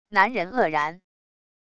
男人愕然wav音频